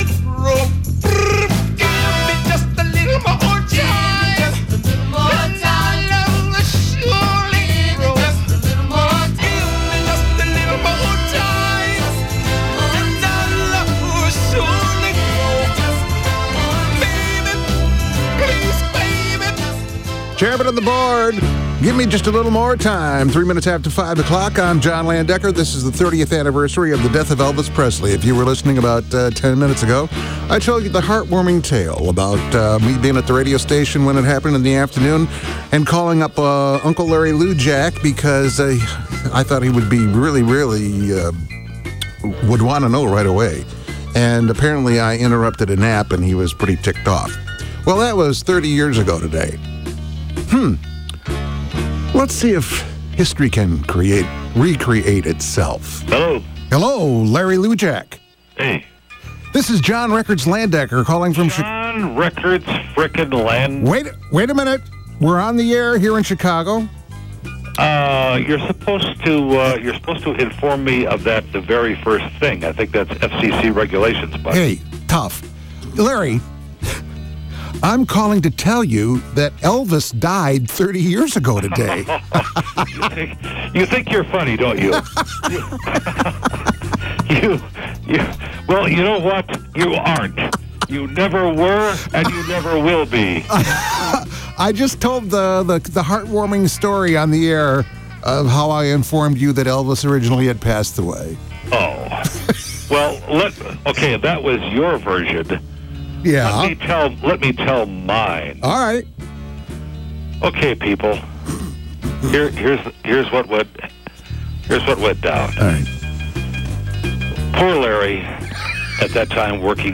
I’ve tracked down the audio of John & Larry reliving that moment, 30 years later…